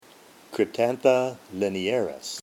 Pronunciation/Pronunciación:
Cryp-tán-tha li-ne-à-ris